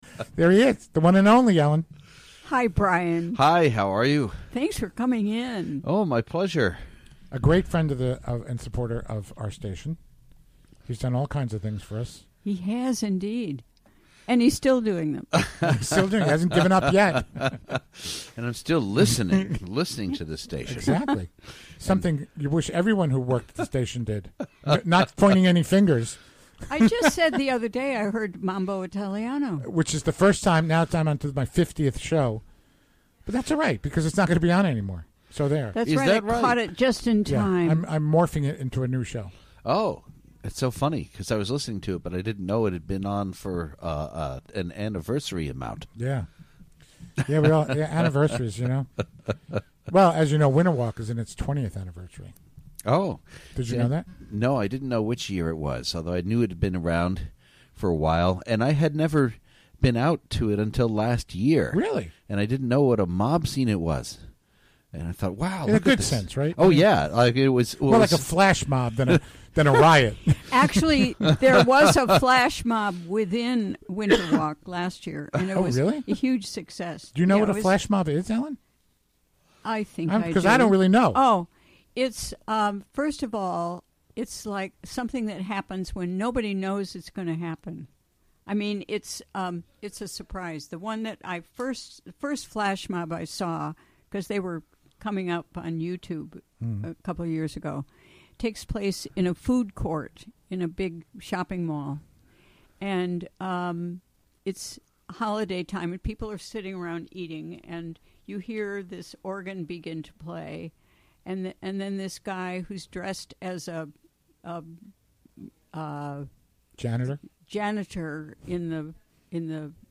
6pm The show features local news, interviews with comm...
Recorded in WGXC's Hudson Studio and broadcast on the WGXC Afternoon Show Thursday, December 1, 2016.